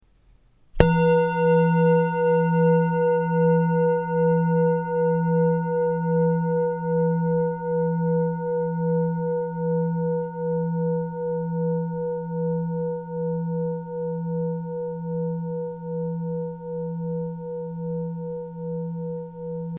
Tibetische Klangschale - BECKENSCHALE
Gewicht: 1430 g
Durchmesser: 21,6 cm
Grundton: 166,98 Hz
1. Oberton: 484,63 Hz